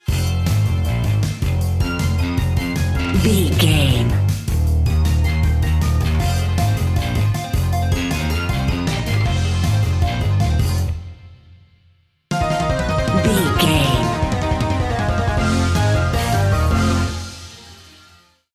Dorian
aggressive
driving
energetic
frantic
intense
bass guitar
synthesiser
percussion
electric piano